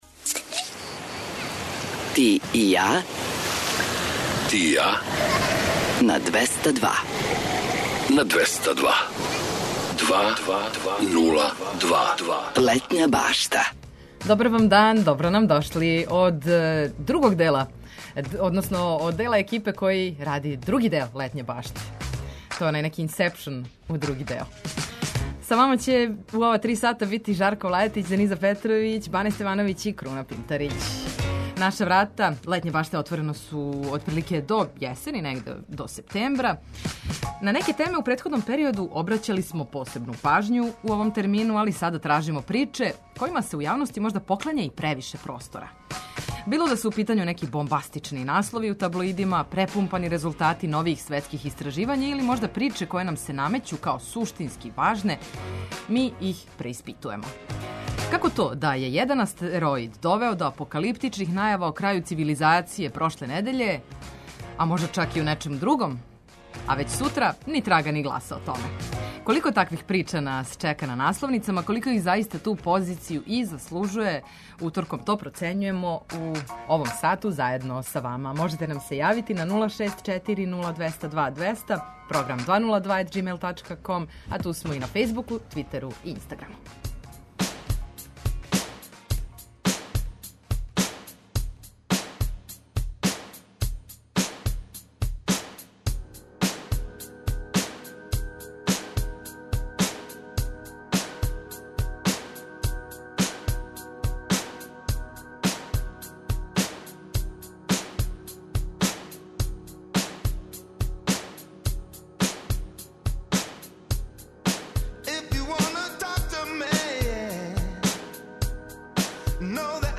Док се Сунце полако премешта на западну страну неба, ми се премештамо у више музике, приче о песмама, важним албумима, рођенданима музичара, а завирићемо и у највеће хитове светских топ листа.
Предлажемо вам предстојеће догађаје широм Србије, пратимо сервисне информације важне за организовање дана, а наш репортер је на градским улицама, са актуелним причама.